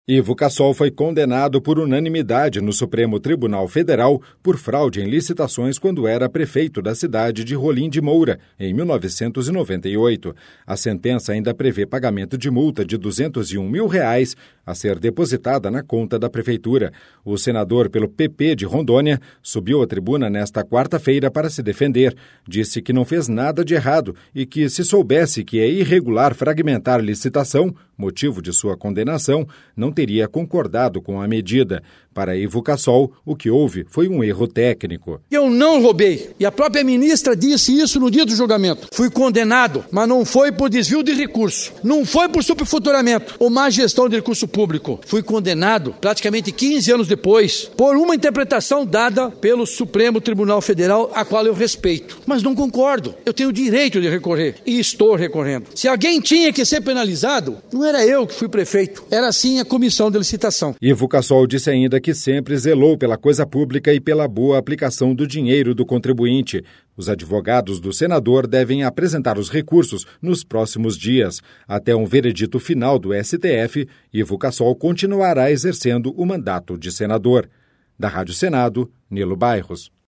O senador pelo PP de Rondônia subiu à tribuna nesta quarta-feira para se defender.